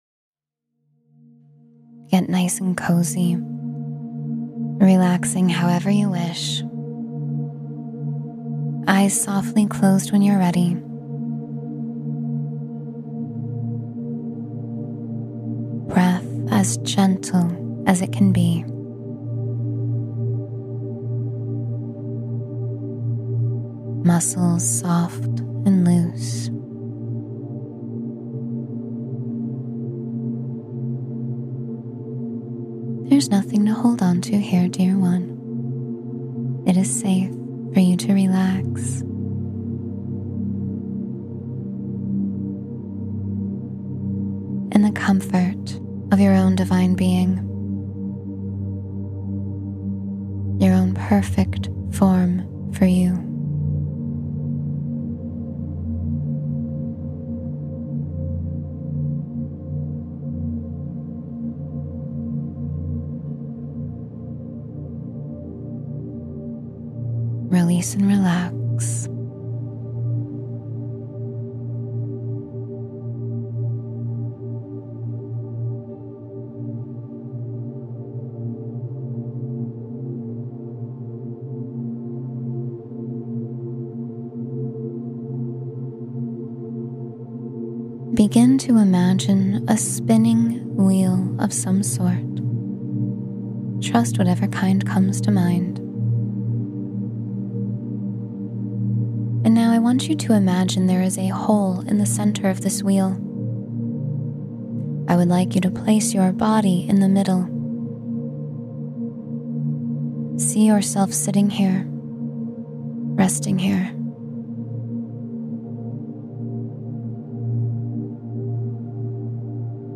Morning Message: Listen to Your Heart — Meditation for Connection and Insight